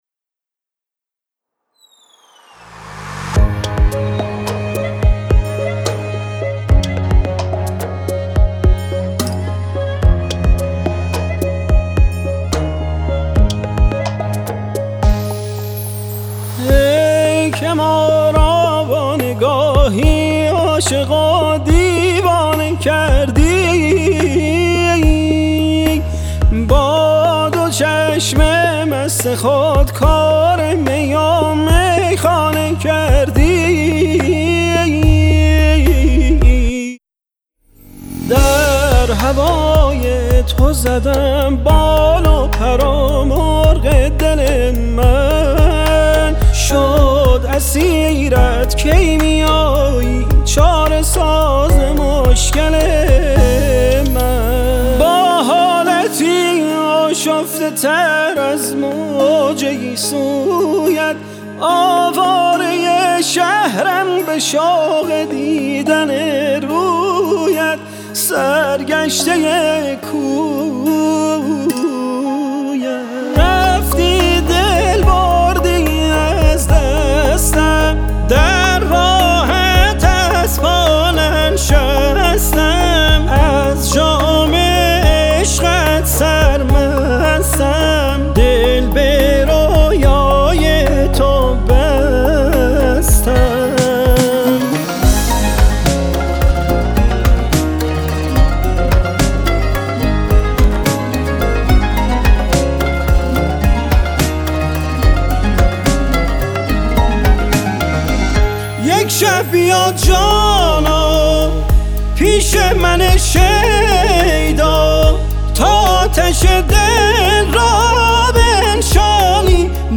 کمانچه
سنتور
تنبک